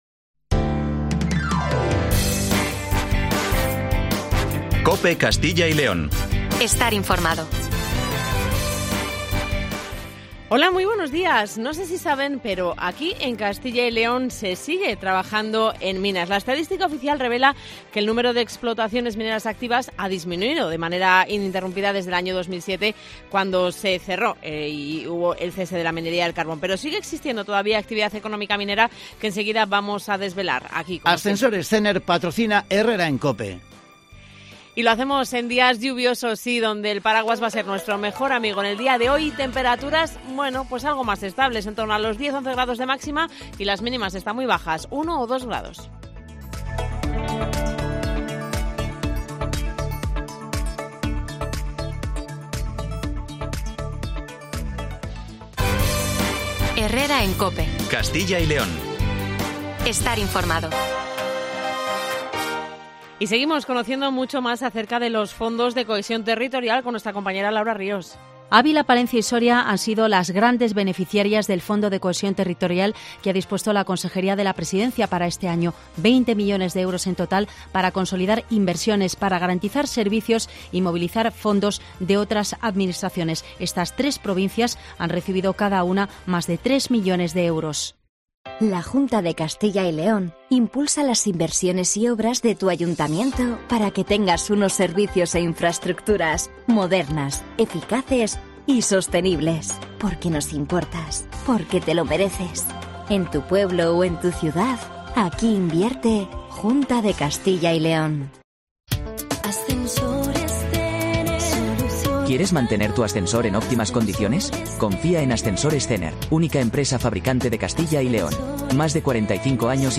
Analizamos el peso que sigue teniendo la minería en la economía de la comunidad con el Director General de Energía y Minas, Alfonso Arroyo González.